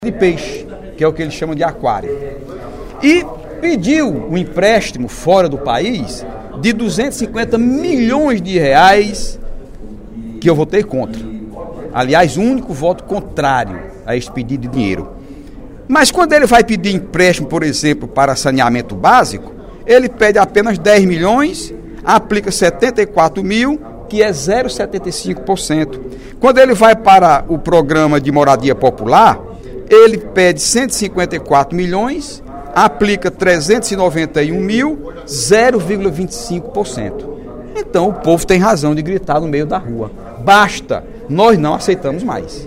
O deputado Heitor Férrer (PDT) afirmou, durante o primeiro expediente desta quinta-feira (20/06), que as manifestações realizadas em todo o país são uma resposta à falta de políticas públicas que atendam às necessidades básicas da população.